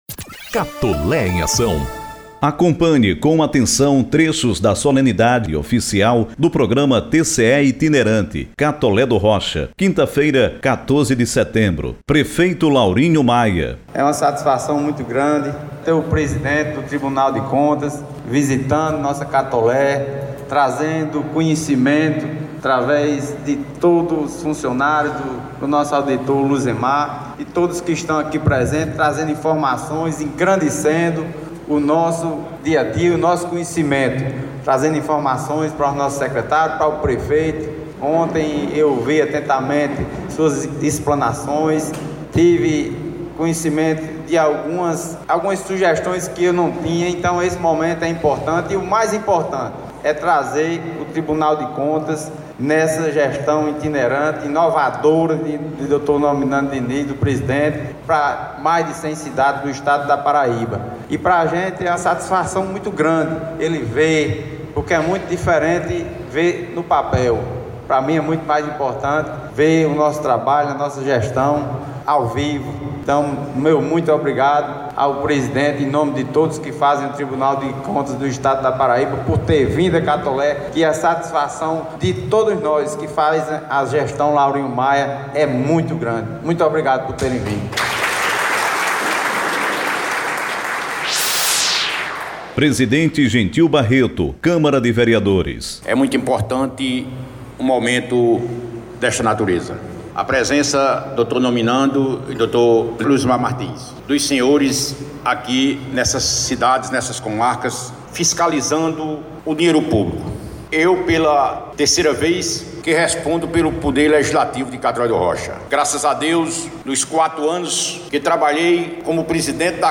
H-REPORTAGEM-No-01-Solenidade-Oficial-do-Programa-TCE-Itinerante-Catole-do-Roch.mp3